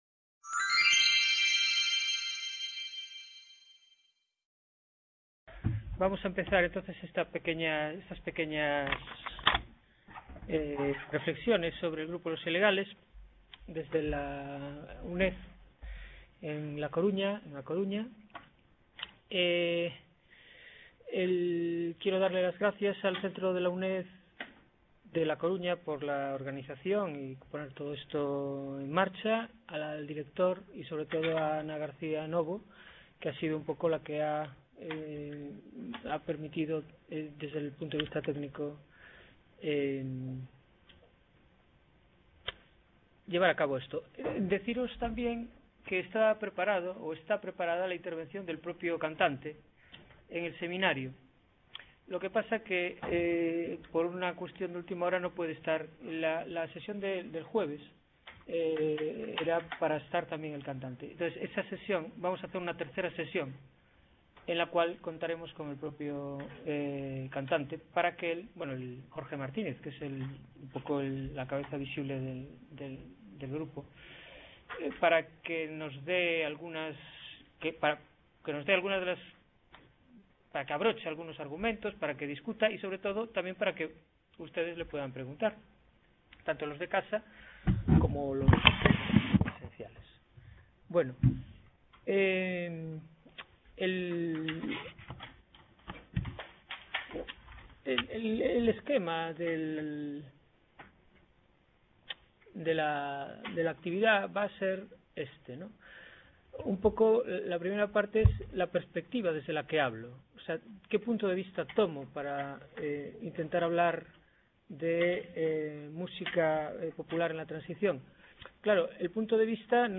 El seminario estará dividido en dos sesiones, que tratarán un ejemplo de la cultura popular de la Transición española a la democracia, el grupo musical Los Ilegales.